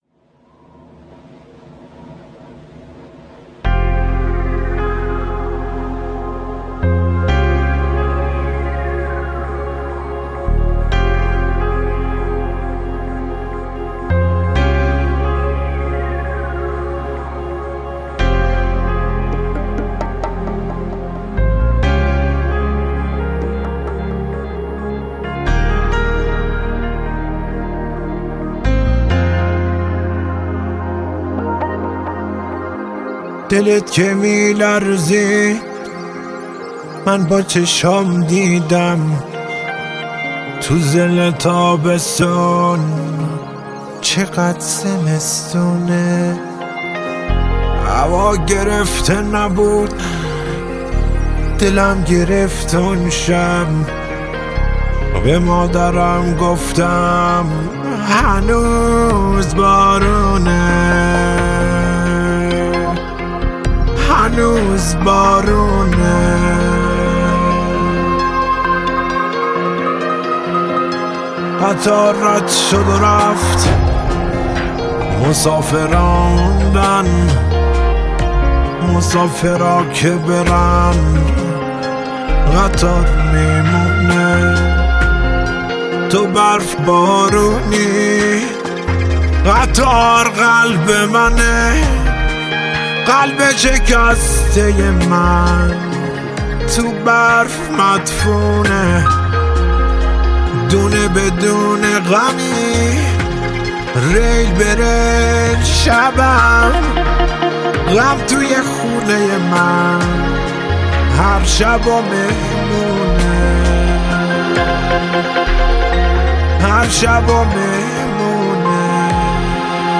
پاپ و راک